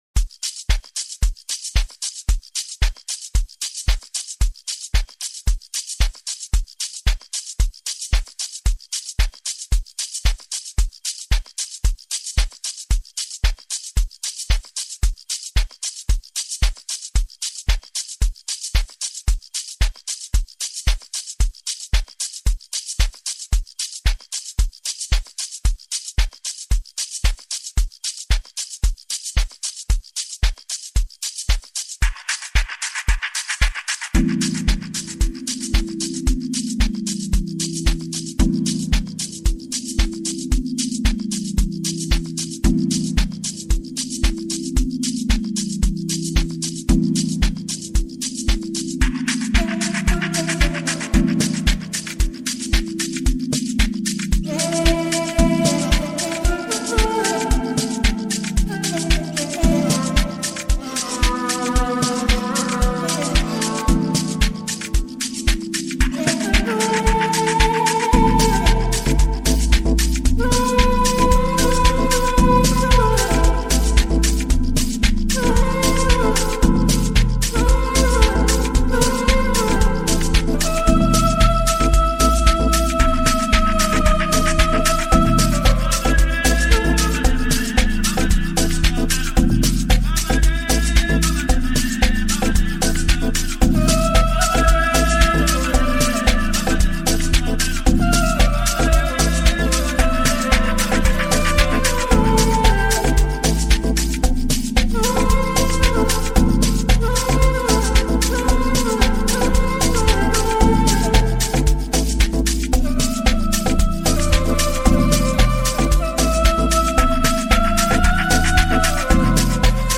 The song is filled with very soulful emotional elements